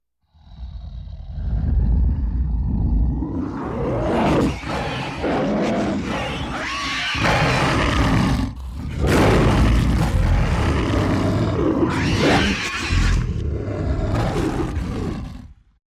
monster-sound